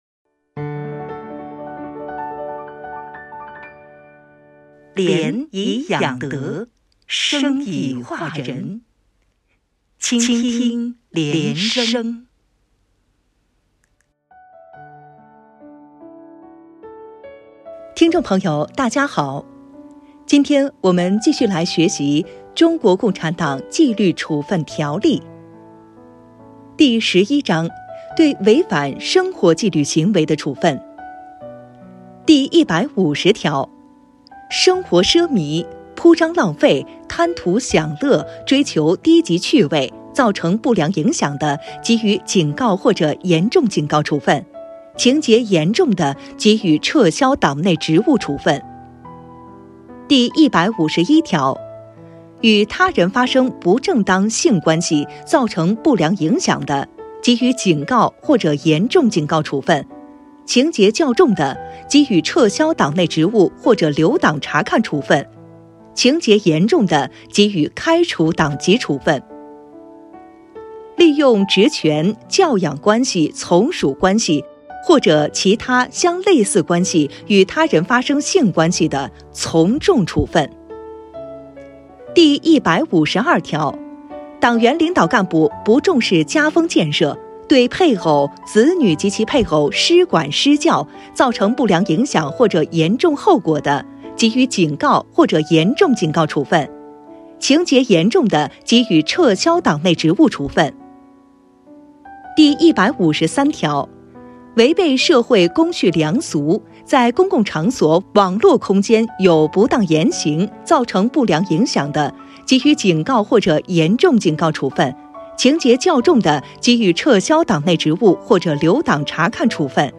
原文诵读系列音频